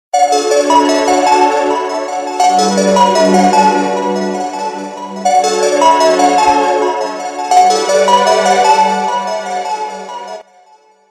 Home > Ringtones Mp3 > SMS Tone > New Collection Home